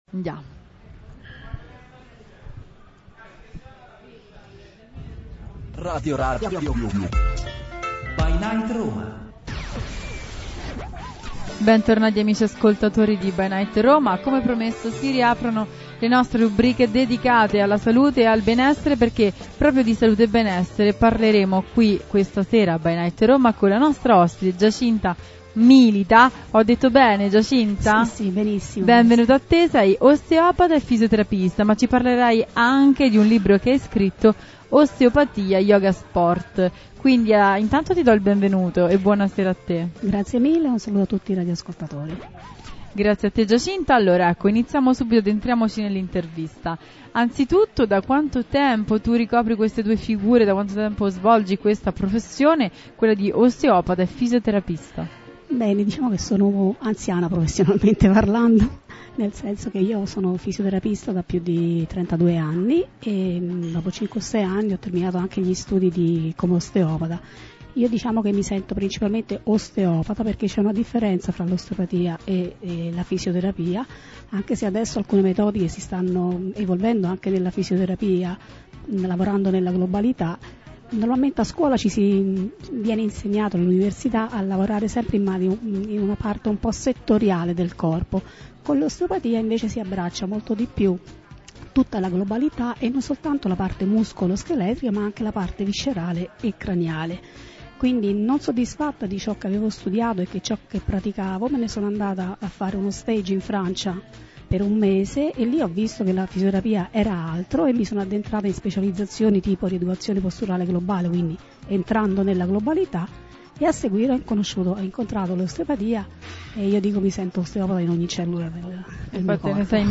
INTERVISTA A RADIO RADIO BY NIGHT - Novembre 2017 ByNight Roma